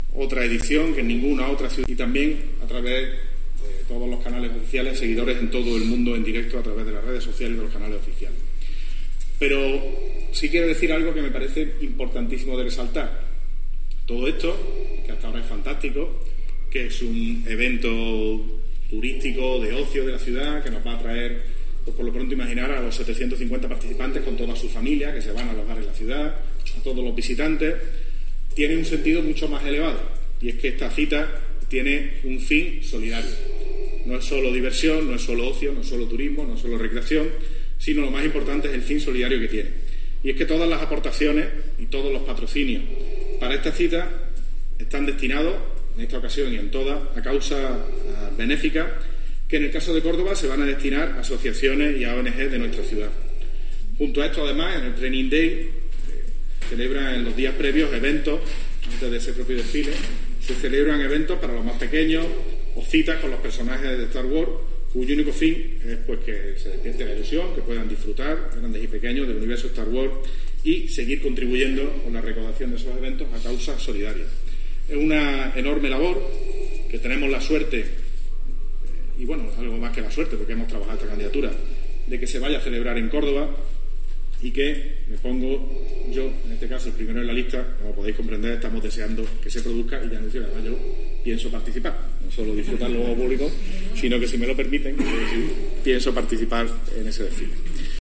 Bellido hablando del training day de Star Wars